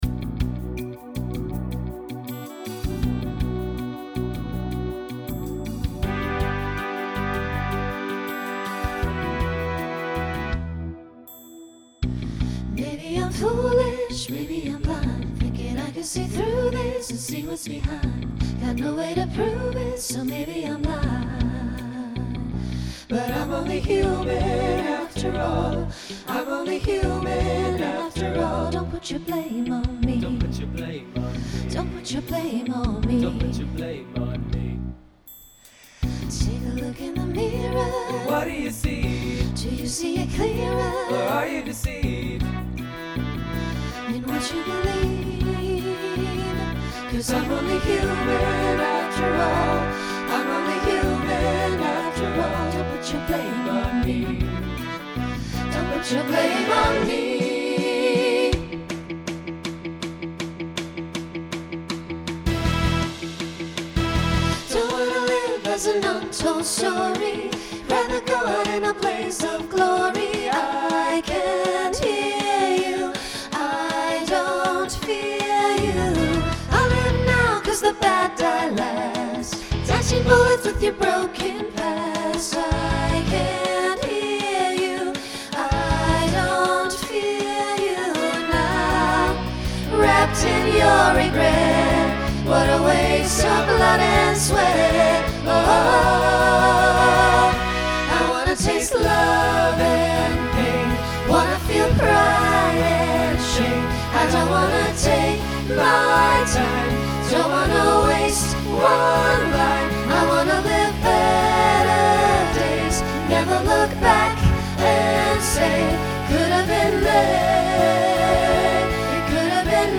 Genre Folk , Rock Instrumental combo
Opener Voicing SATB